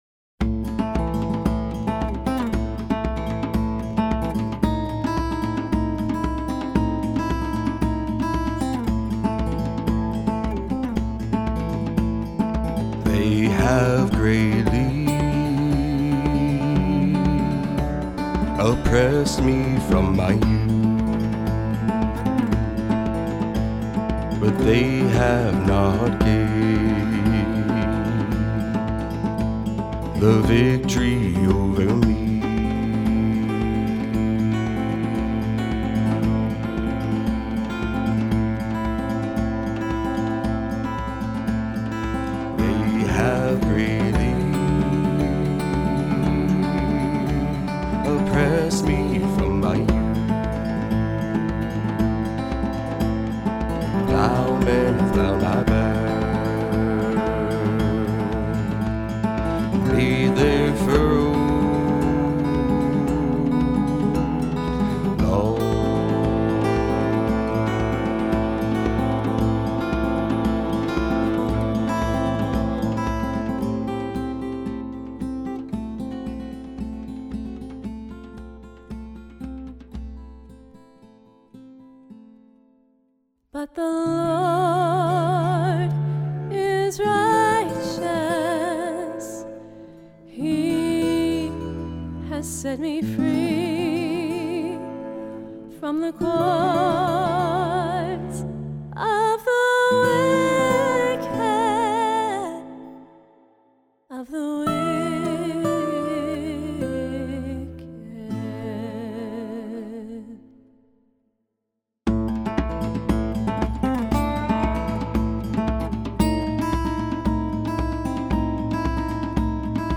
Djembe Drums
Cello